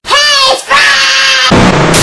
Worms speechbanks
Kamikaze.wav